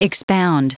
Prononciation du mot expound en anglais (fichier audio)
Prononciation du mot : expound